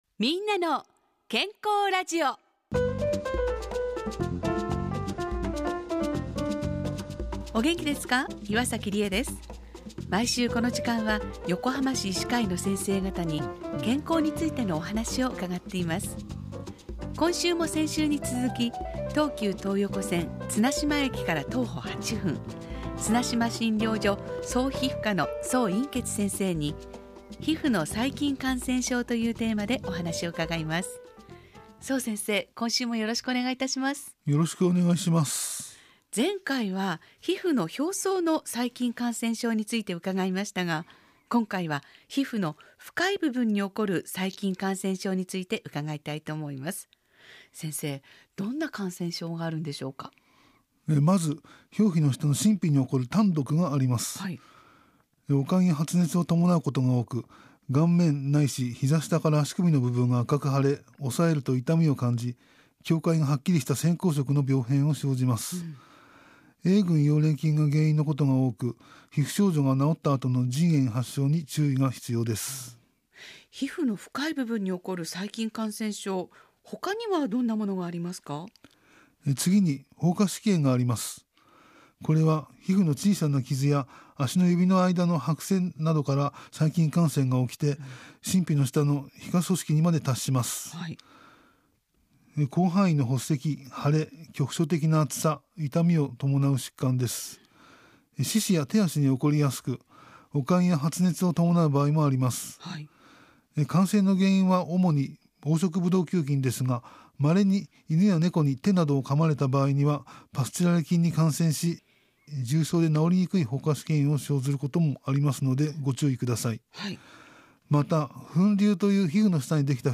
ラジオ番組 みんなの健康ラジオ